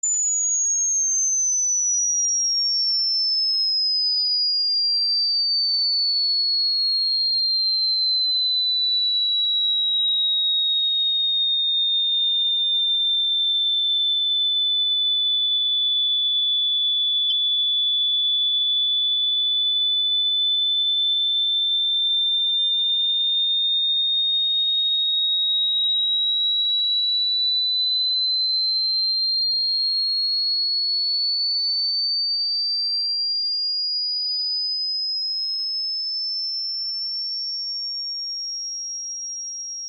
Âm thanh đuổi Chó MP3